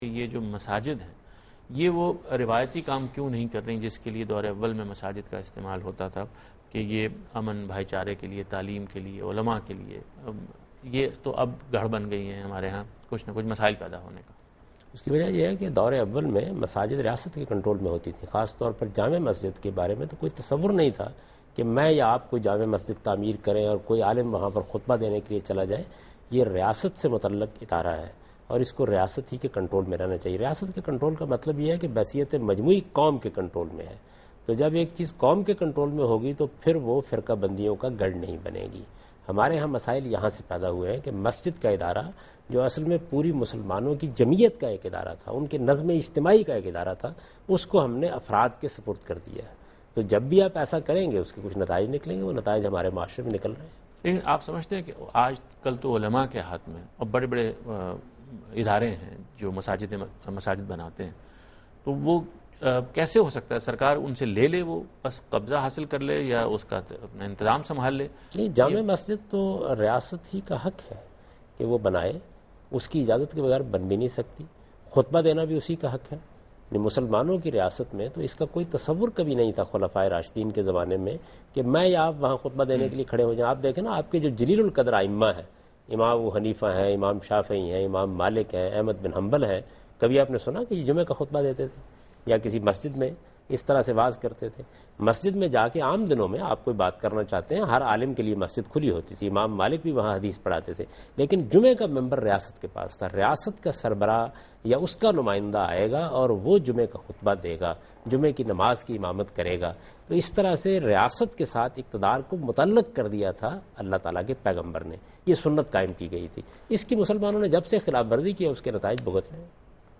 Category: TV Programs / Dunya News / Deen-o-Daanish / Questions_Answers /
Answer to a Question by Javed Ahmad Ghamidi during a talk show "Deen o Danish" on Duny News TV